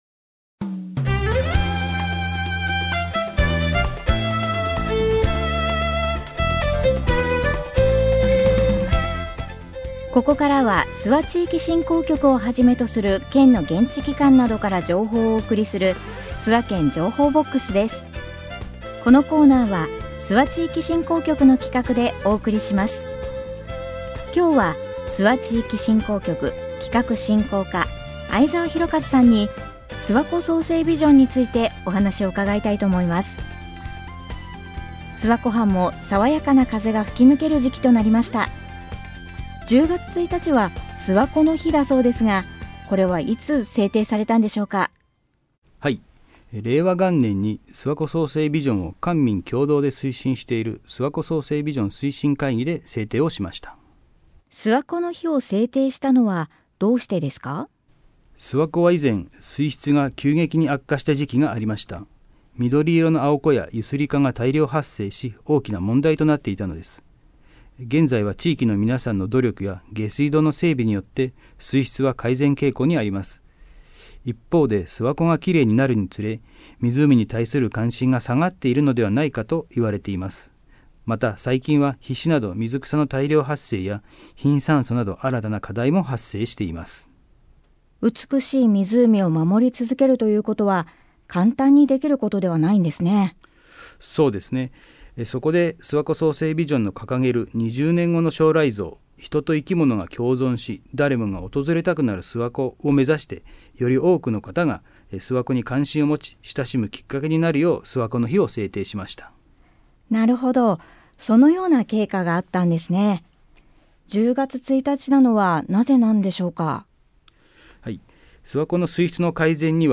コミュニティエフエムを活用した地域情報の発信